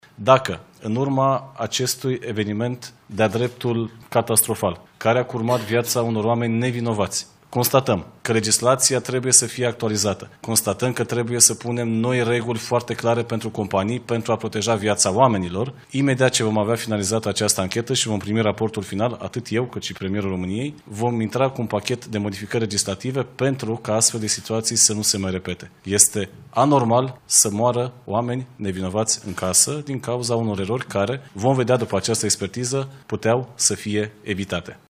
Ministrul Energiei, Bogdan Ivan: „Legislația trebuie să fie actualizată și că trebuie să stabilim reguli foarte clare pentru companii”